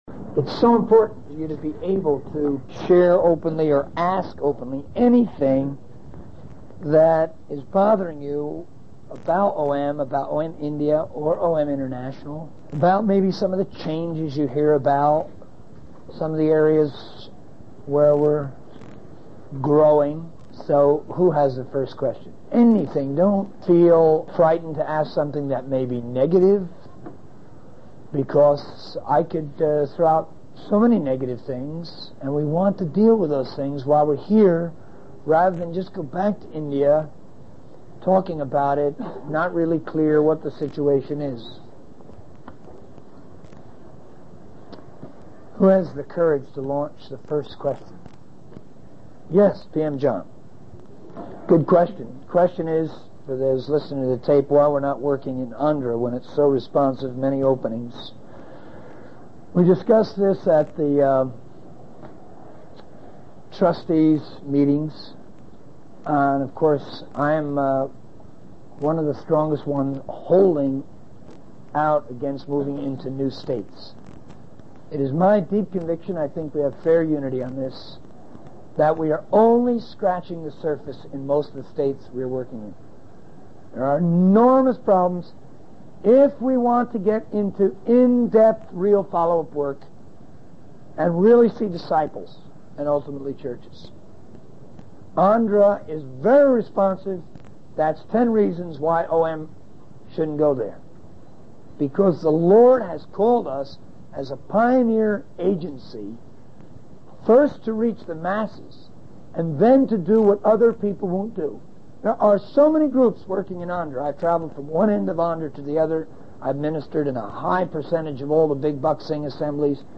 Q+a Kathmandu